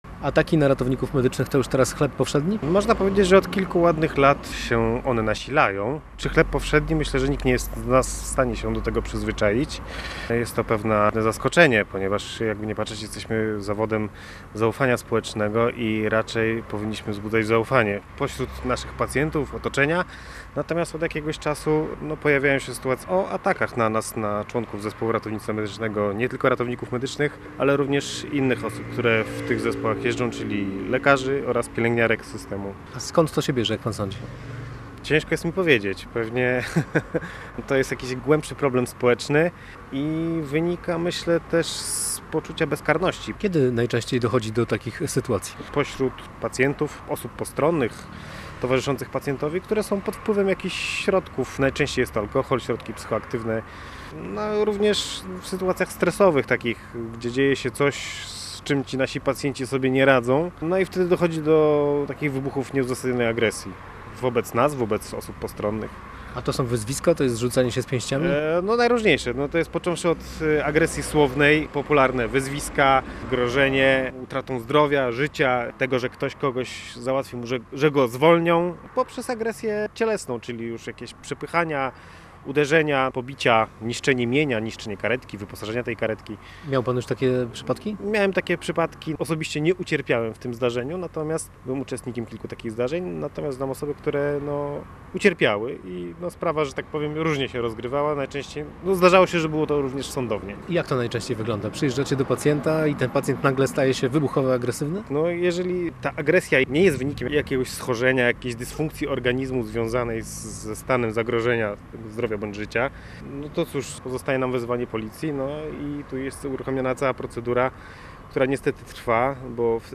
Posłuchaj materiału naszego reportera: https